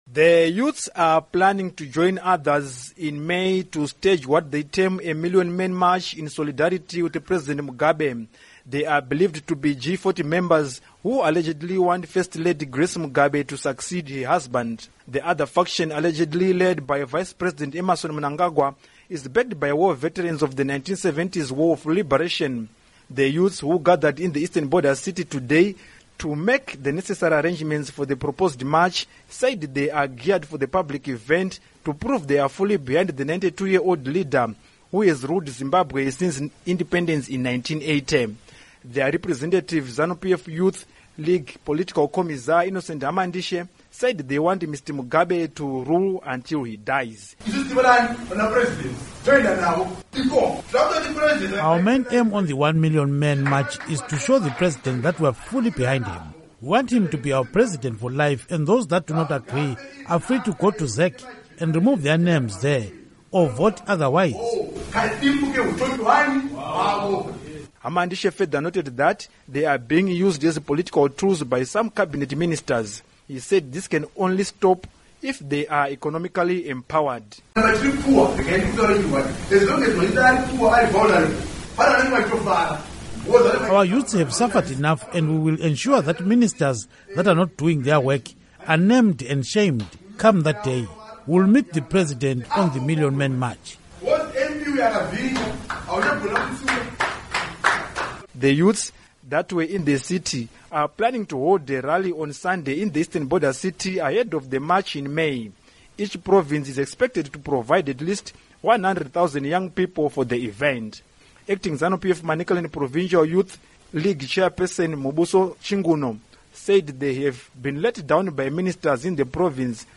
Report on Zanu PF Youth